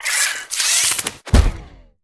Sfx_tool_spypenguin_deploy_01.ogg